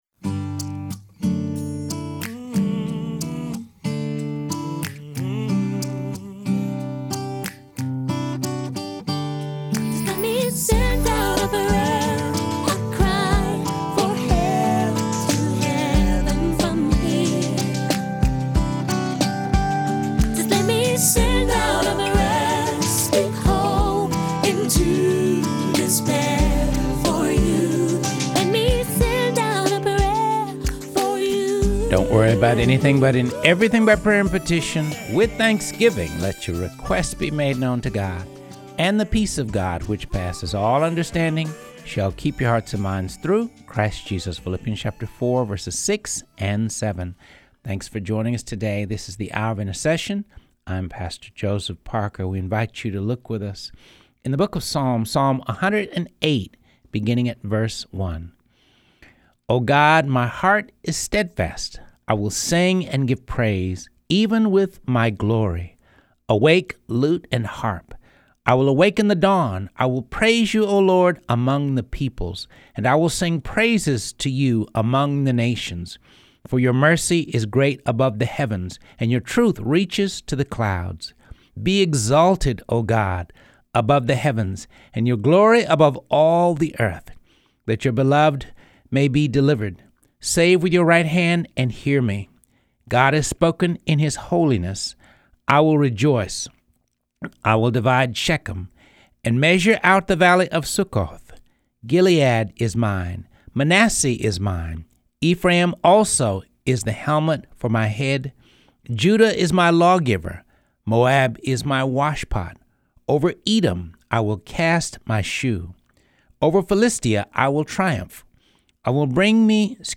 Teaching: Lessons on Prayer from the Book of Genesis